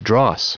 Prononciation du mot dross en anglais (fichier audio)
Prononciation du mot : dross